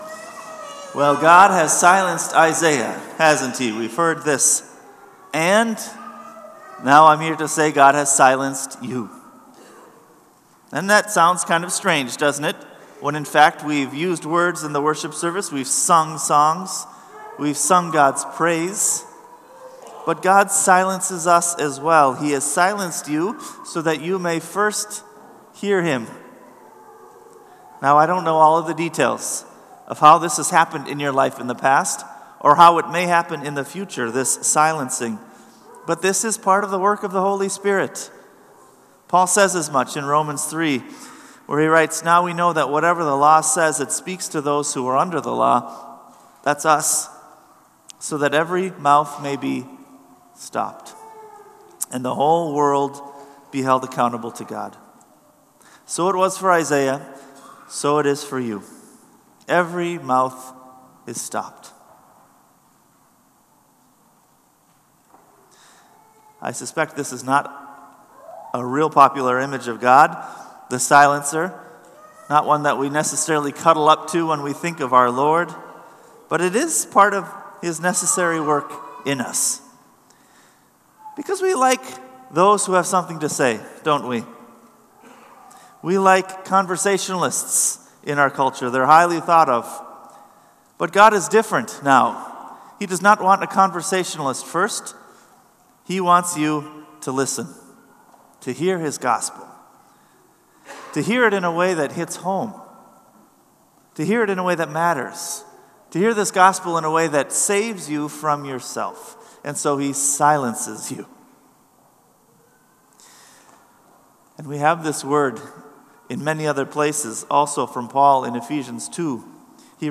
Sermon”From Silent to Sent”